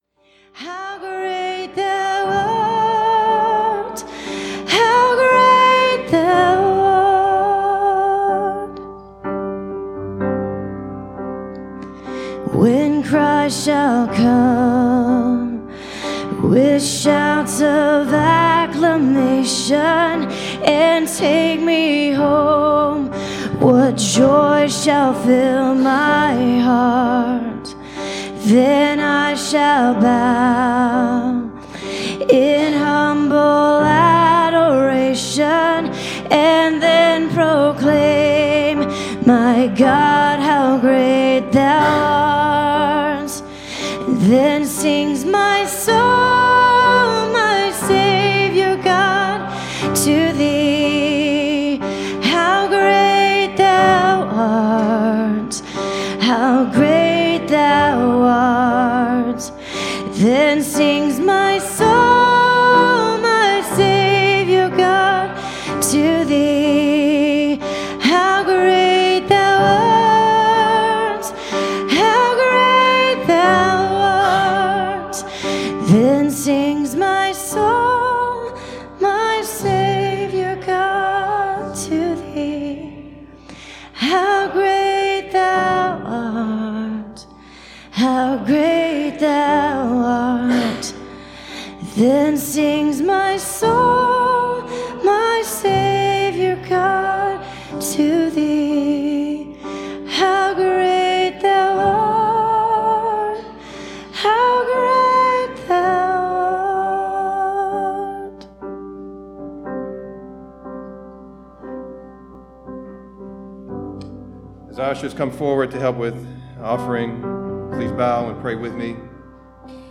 Please, click the arrow below to hear this week's service.